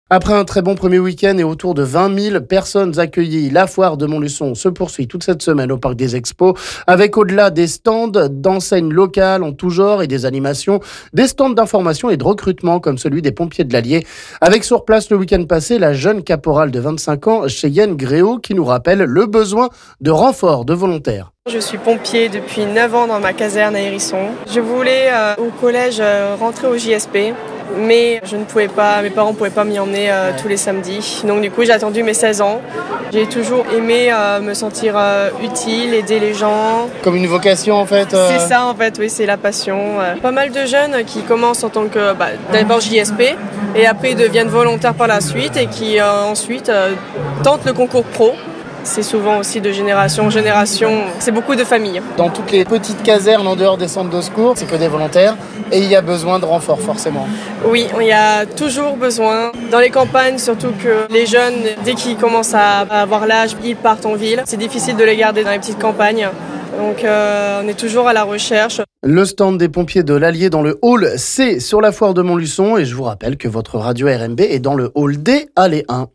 Les pompiers de l'Allier sont présents sur la Foire de Montluçon pour informer sur le recrutement notamment